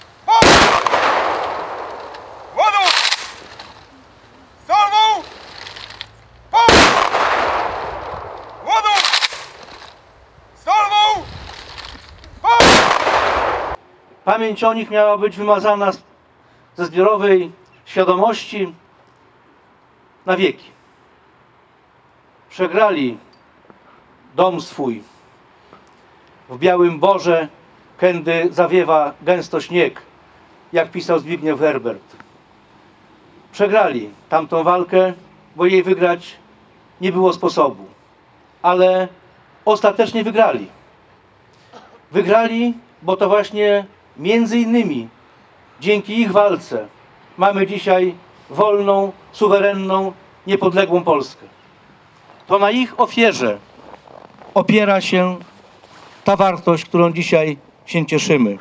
Podczas okolicznościowego wystąpienia głos zabrał poseł i wiceminister Jarosław Zieliński.